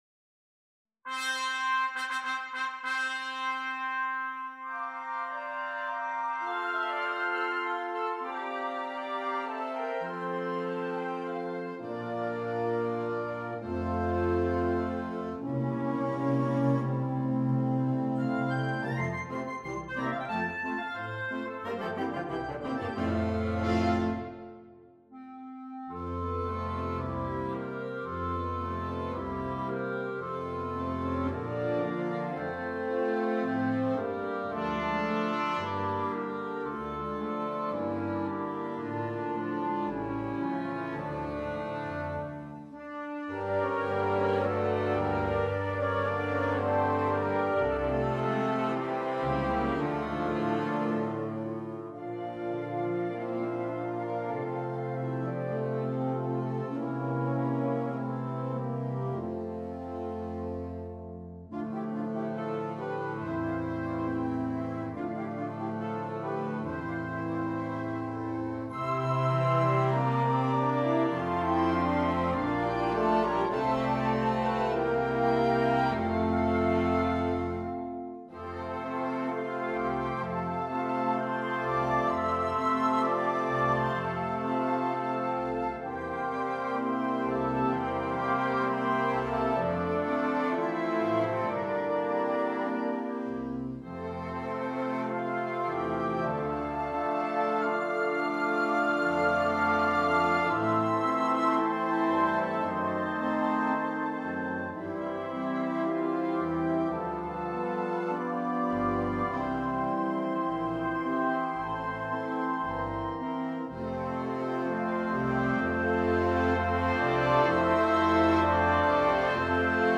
Aria tratta dall’operetta
trascrizione per banda